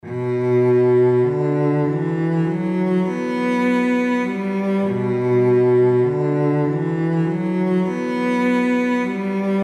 描述：小提琴、大提琴和铜管
Tag: 88 bpm Rap Loops Strings Loops 3.67 MB wav Key : A